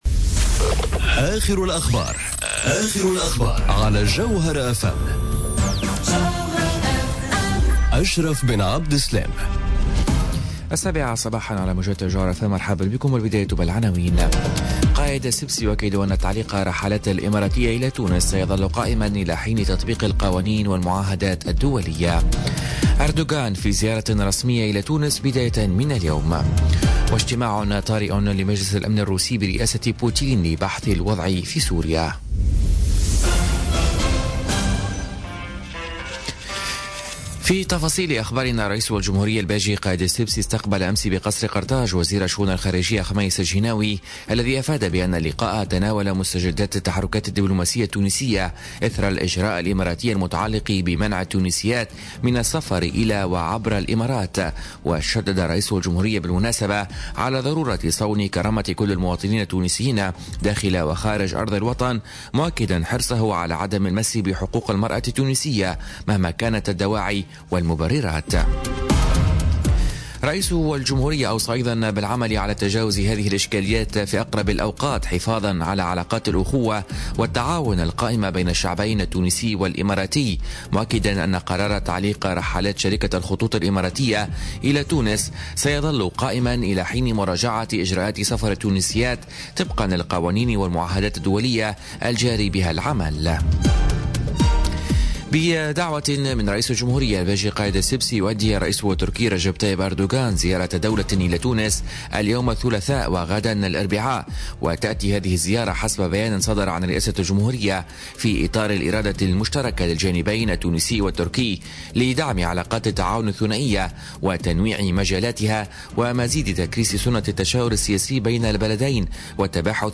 نشرة أخبار السابعة صباحا ليوم الثلاثاء 26 ديسمبر 2018